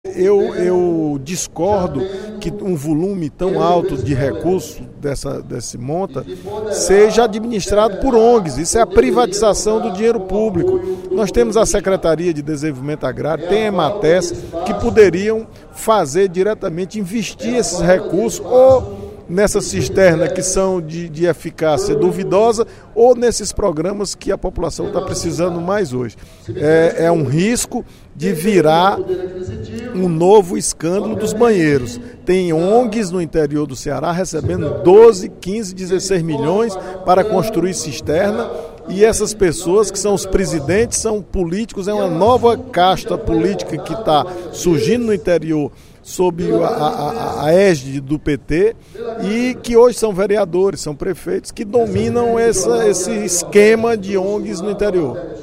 O deputado João Jaime (PSDB) cobrou, nesta quarta-feira (24/04), durante o primeiro expediente, esclarecimento do secretário de Desenvolvimento Agrário, Nelson Martins, em relação aos valores investidos no programa de cisternas de enxurradas e na contratação de ONGs para executar o serviço.